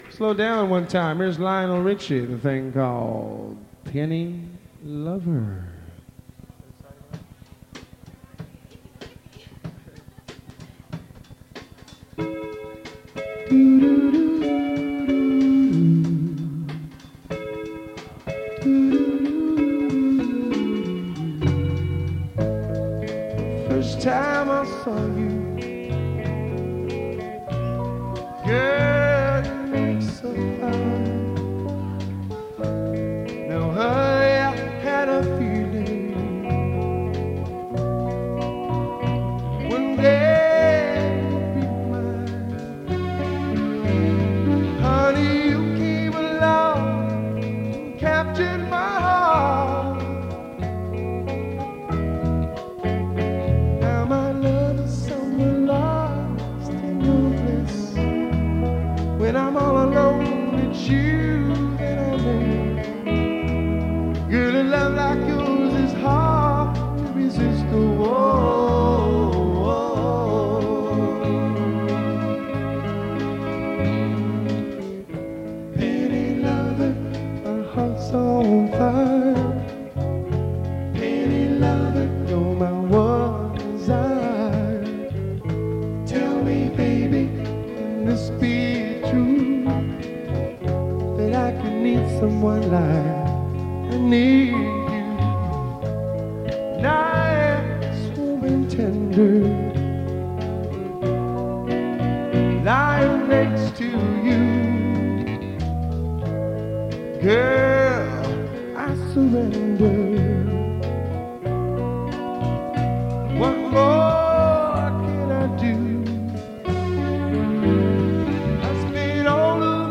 sharing the lead vocals